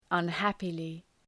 Προφορά
{ʌn’hæpılı}